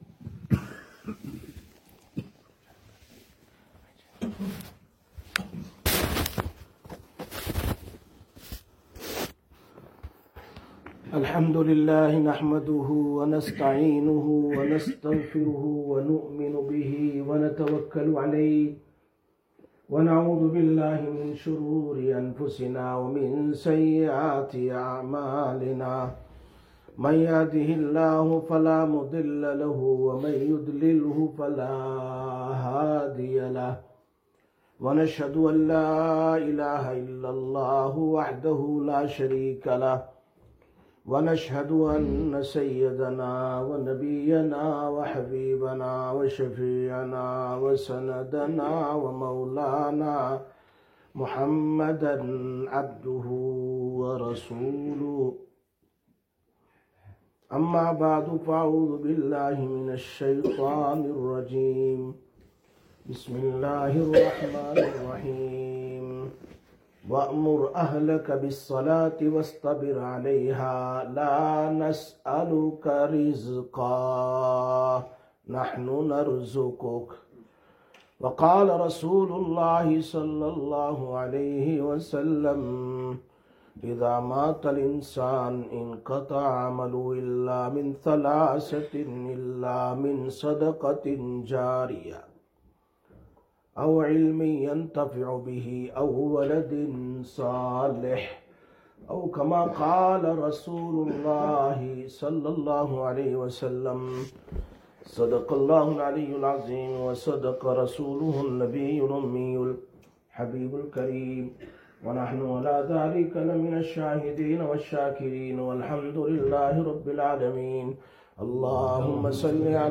09/01/2026 Jumma Bayan, Masjid Quba